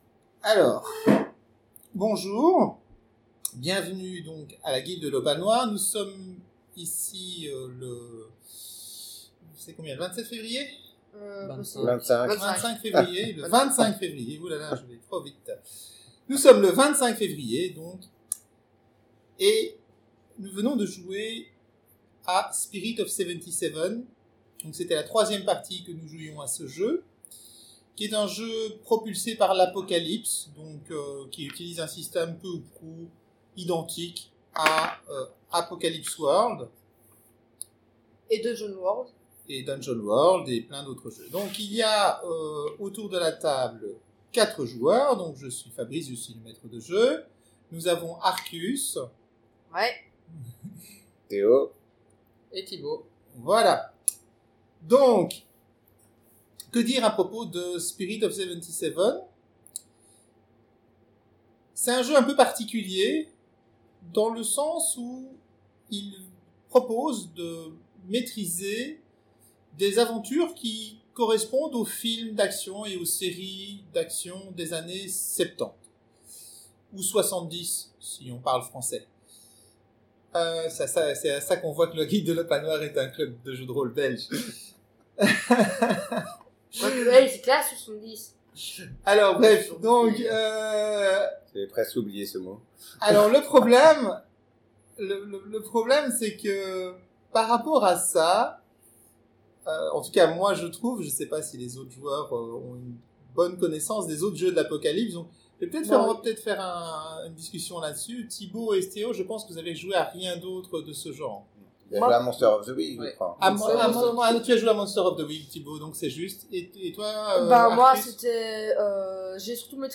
La Guilde de l'Opale Noire est un club de jeu de rôle de la région de Charleroi. Le Podcast de l'Opale Noire est enregistré sur place et porte sur les jeux qui sont joués à la Guilde.